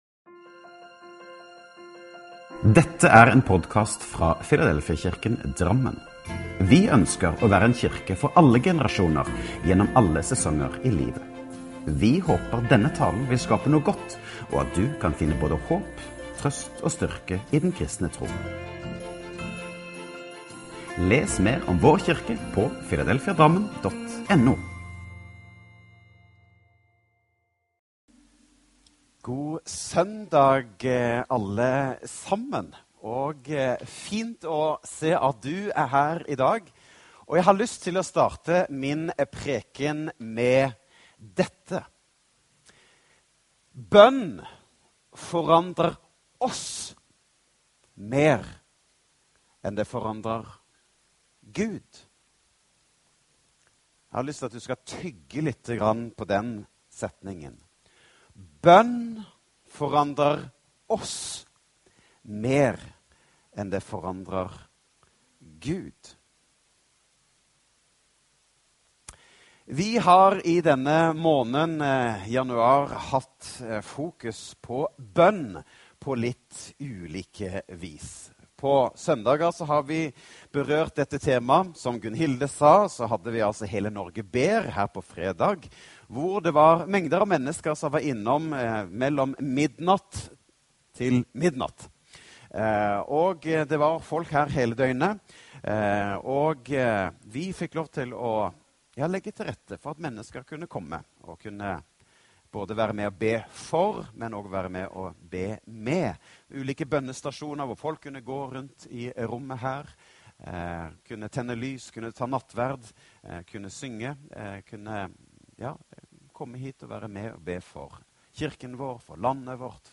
Last ned talen til egen maskin eller spill den av direkte: